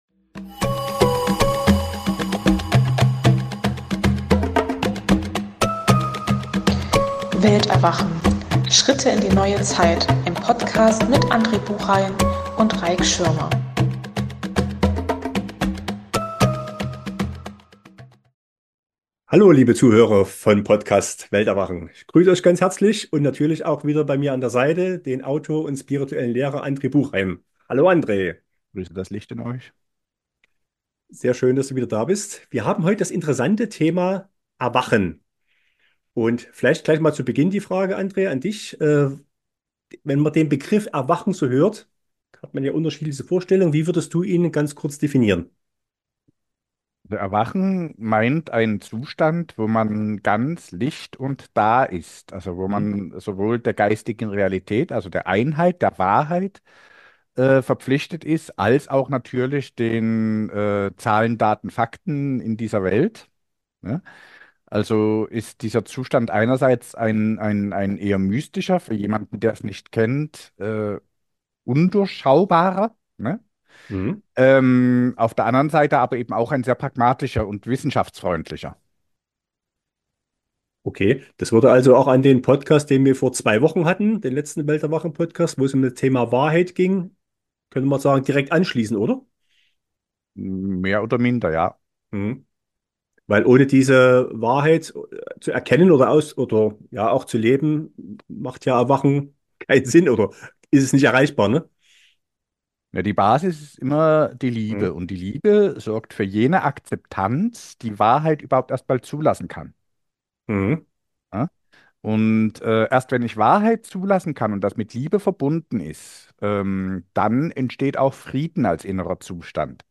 In einem lockeren Gespräch wird das Thema "Erwachen" behandelt und die unterschiedlichsten Aspekte / Ansichten dazu erläutert, sowie ein praktisches Beispiel dargelegt.